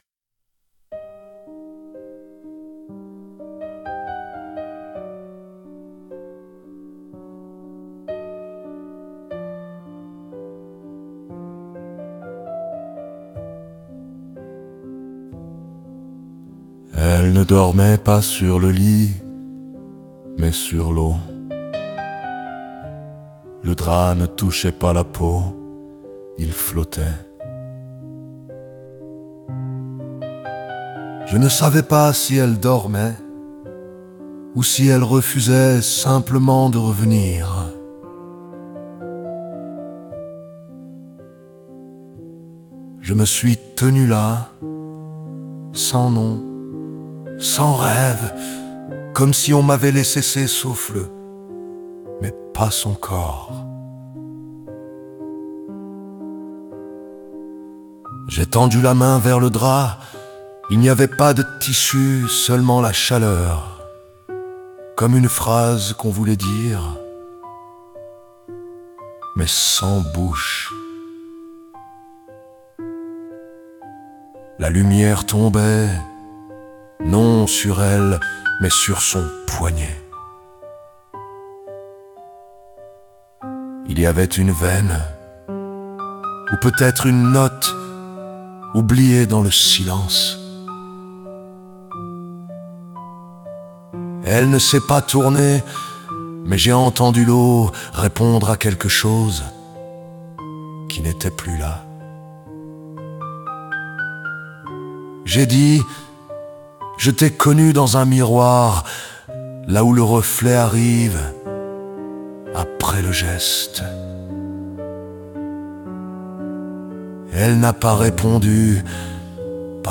somatopoème